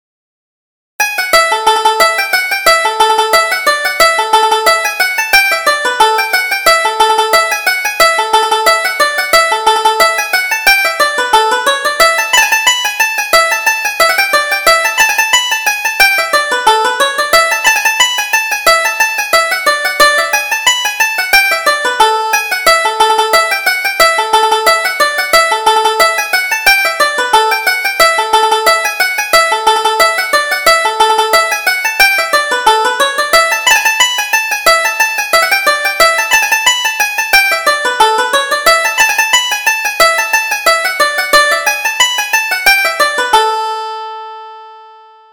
Reel: Dan McCarthy's Fancy